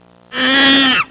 Monkey Screech Sound Effect
Download a high-quality monkey screech sound effect.
monkey-screech.mp3